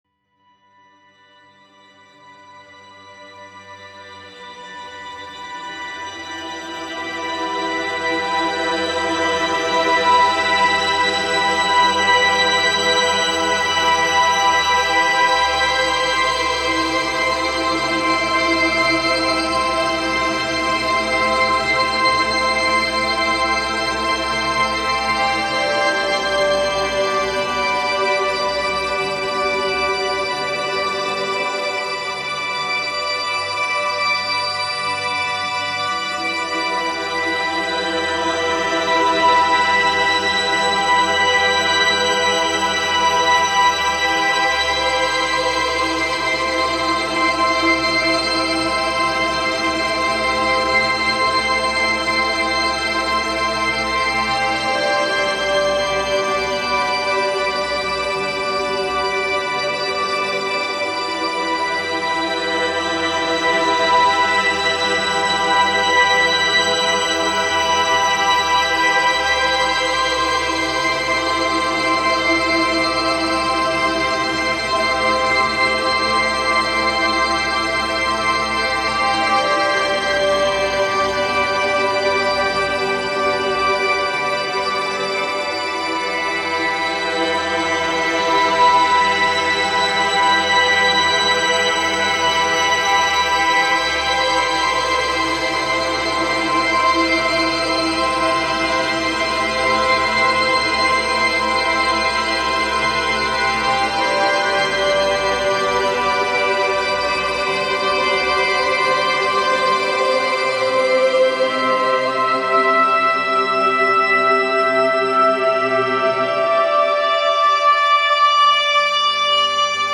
generative audiovisual piece